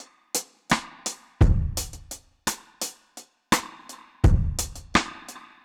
Index of /musicradar/dub-drums-samples/85bpm
Db_DrumsB_Wet_85-01.wav